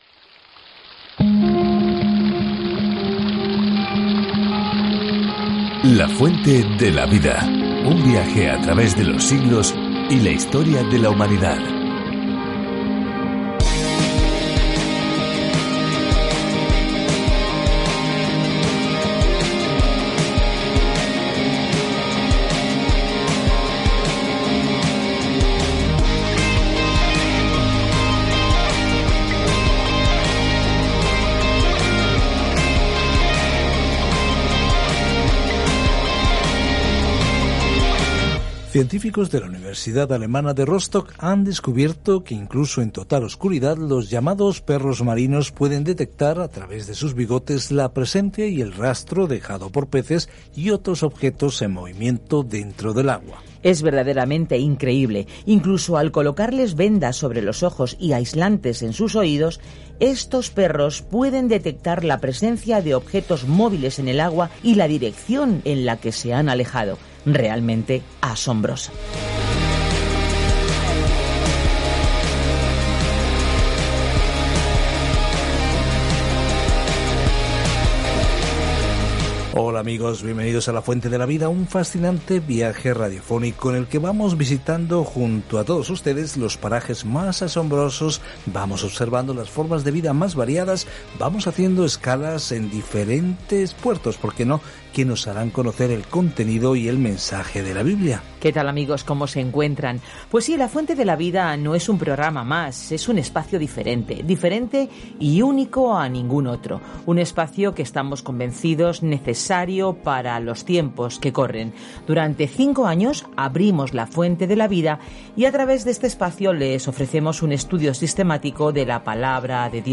Escritura 2 CORINTIOS 6:1-10 Día 10 Iniciar plan Día 12 Acerca de este Plan El gozo de las relaciones dentro del cuerpo de Cristo se destaca en la segunda carta a los Corintios mientras escucha el estudio en audio y lee versículos seleccionados de la palabra de Dios. Viaja diariamente a través de 2 Corintios mientras escuchas el estudio en audio y lees versículos seleccionados de la palabra de Dios.